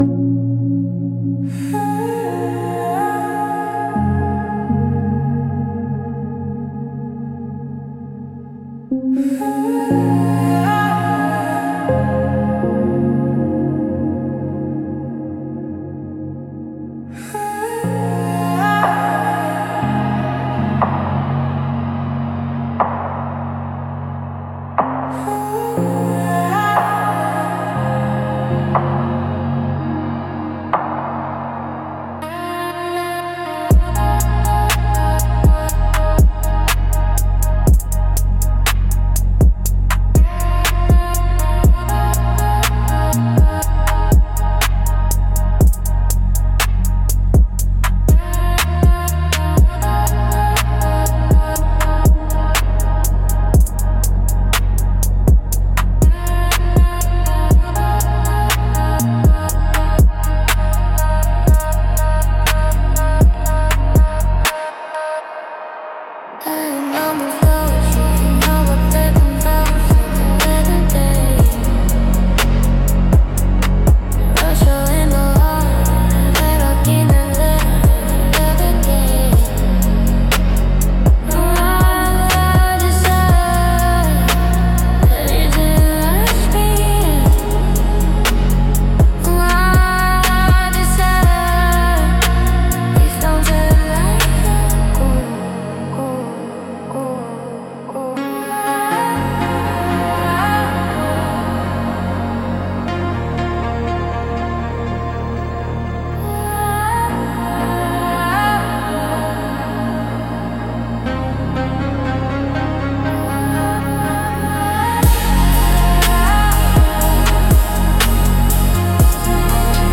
Instrumental - Acheron's Pluck (Acheron is a river of woe in Greek myth) 3.01